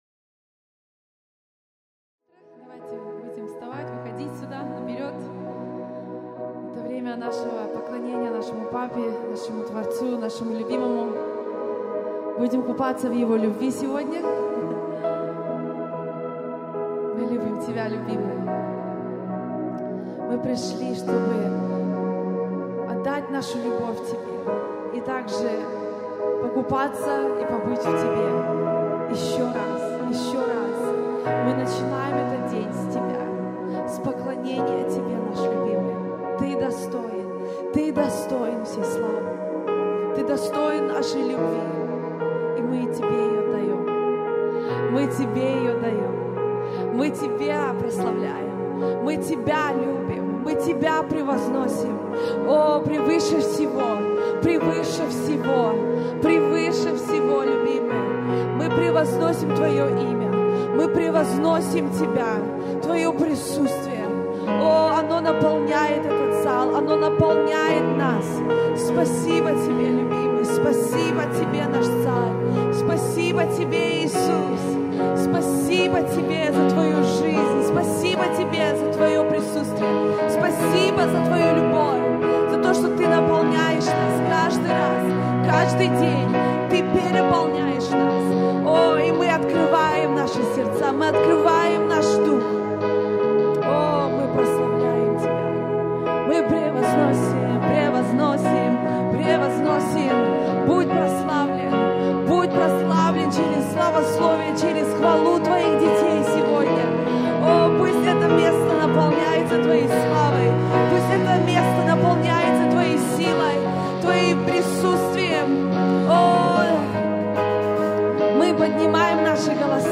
TC Band Live Worship (December 15, 2019).mp3